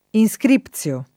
inSkr&pZLo] — anche inscrizione [